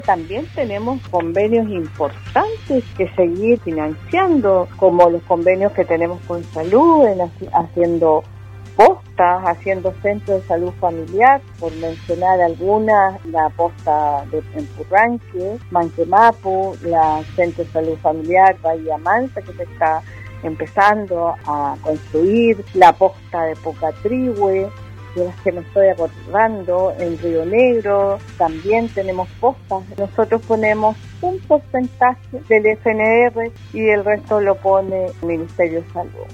En entrevista con Radio Sago, la Core María Angélica Barraza detalló los principales desafíos de su nueva función en el Core Los Lagos hasta el fin de su período en marzo de 2022.